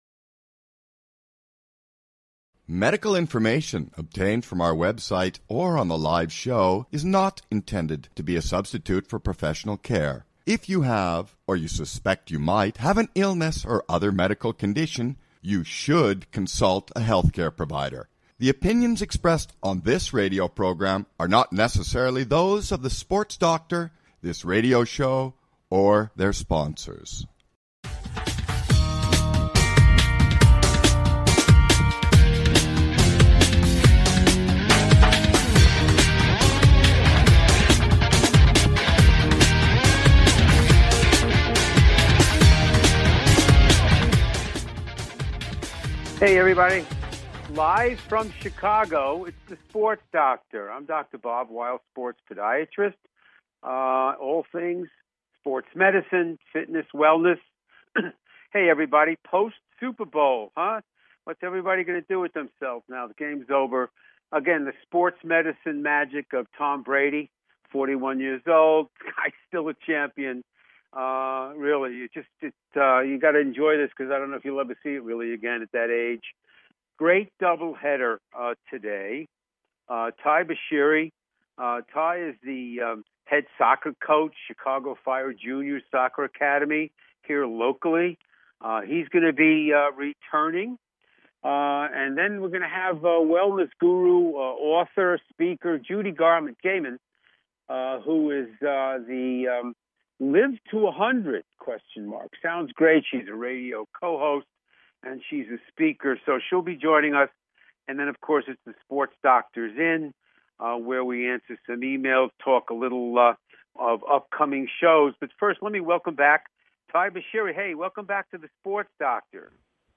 Talk Show Episode, Audio Podcast
Then, it's 'The Sports Doctor's In' with your questions and emails!